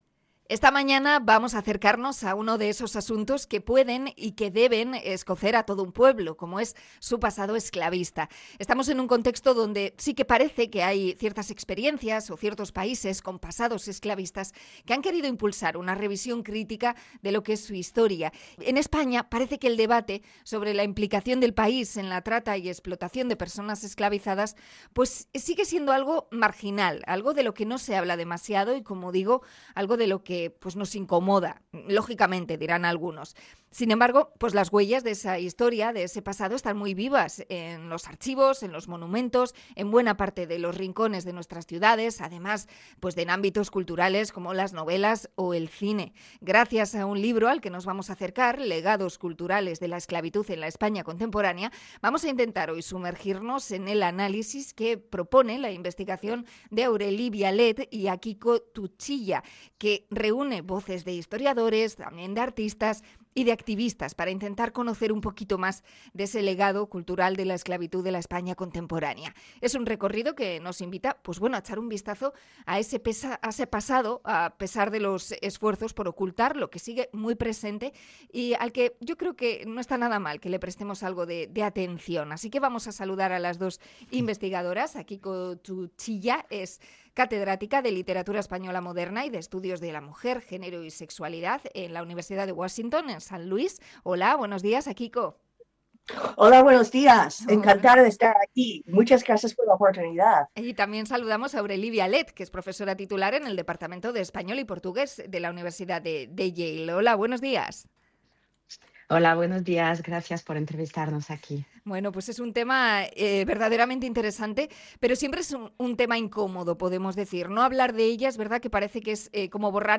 Entrevista a investigadoras sobre el legado de la esclavitud en España